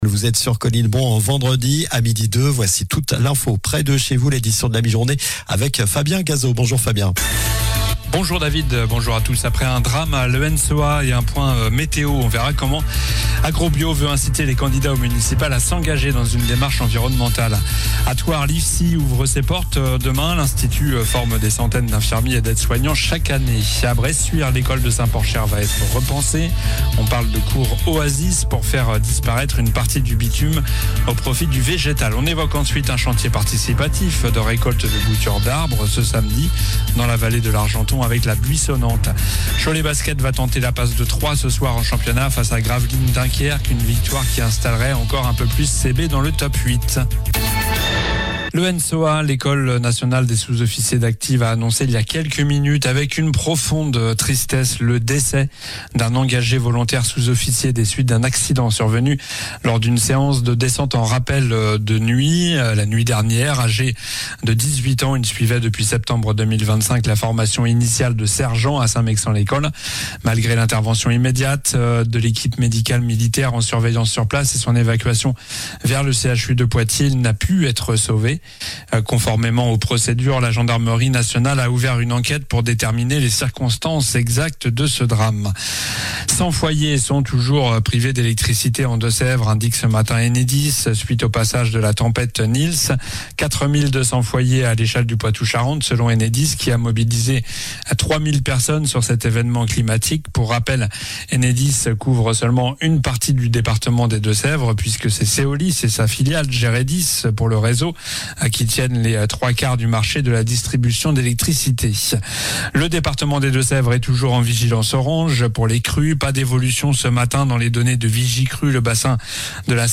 Journal du vendredi 13 février (midi)